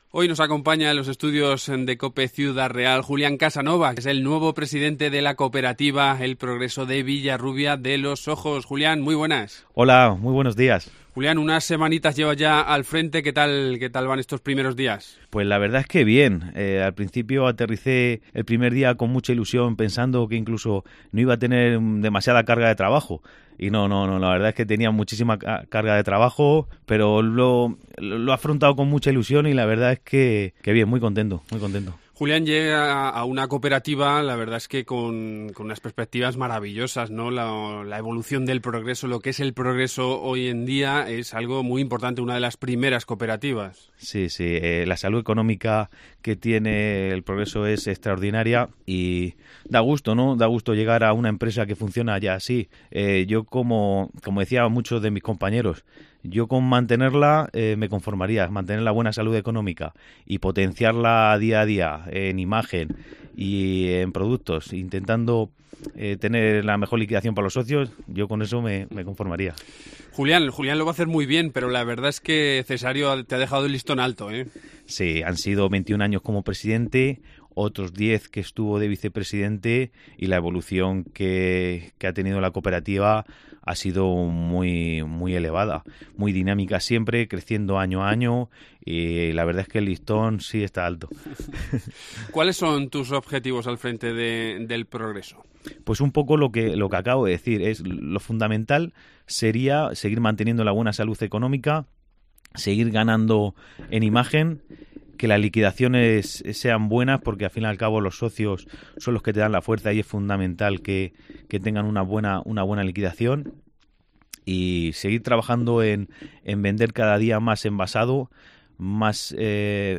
Hoy nos visita aquí en los estudios de Cope y con él vamos a charlar sobre el próspero presente de esta cooperativa y sobre su ilusionante futuro.